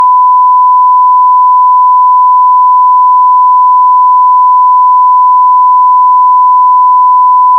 SONAR 3.1 に  1KHz のサイン波を読み込み、それぞれ各周波数に変換した後の波形を見てみました。
変換後、500Hz 以下の周波数でかなり乱れますが、-100dB以下ですので、